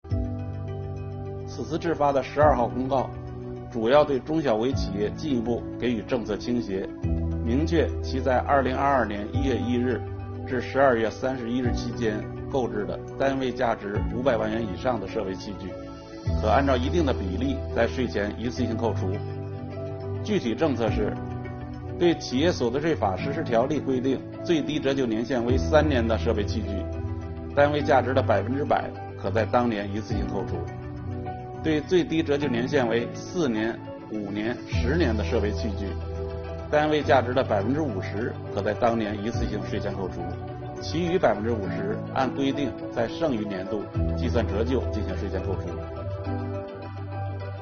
本期课程由国家税务总局所得税司一级巡视员刘宝柱担任主讲人，对中小微企业购置设备器具按一定比例一次性税前扣除政策进行详细讲解，便于征纳双方全面准确理解此项政策，统一政策执行口径。